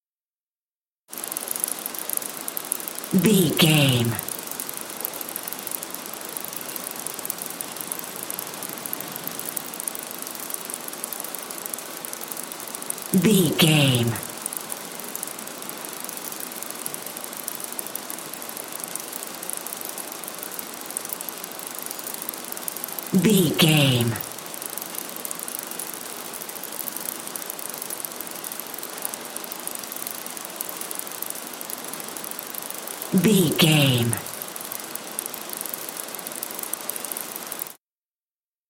Airport baggage carousel cargo
Sound Effects
urban
airport sounds